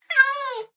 meow1.ogg